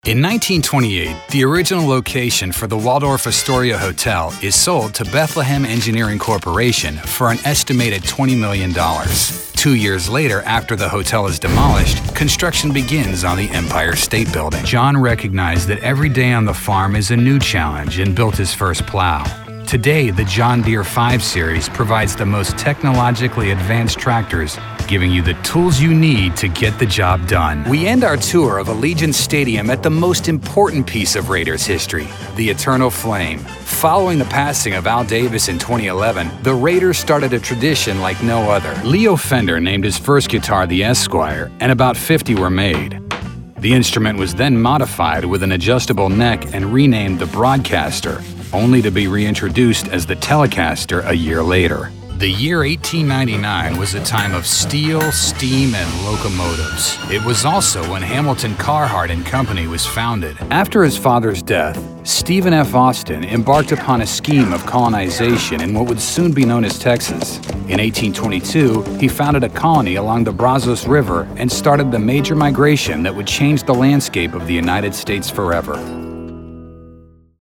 Bright, Upbeat, Youthful.
Narration